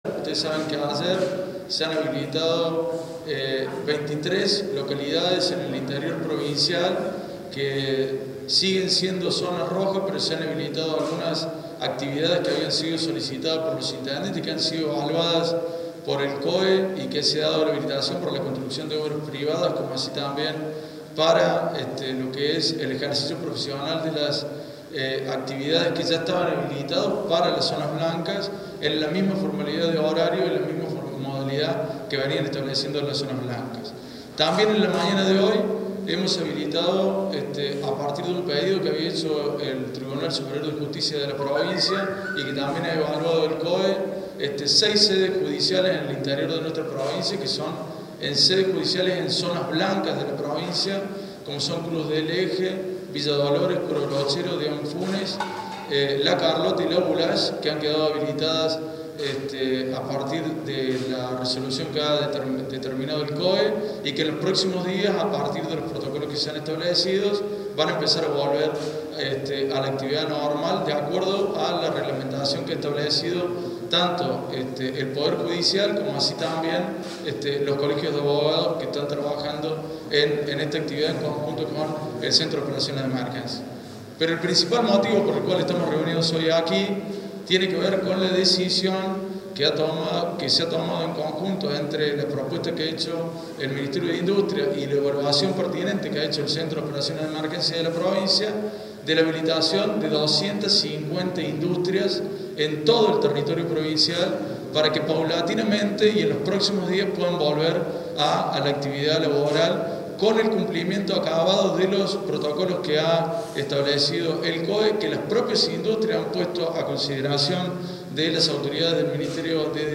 Audio: Manuel Calvo(Vicegobernador de Córdoba).